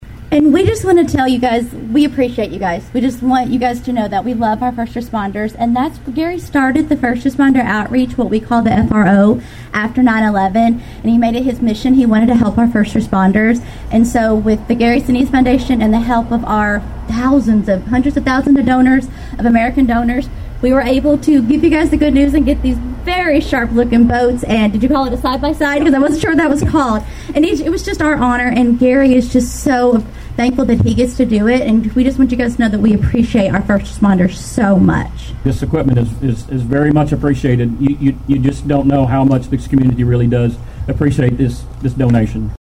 A dedication ceremony took place at the Rives Fire and Rescue building on Monday.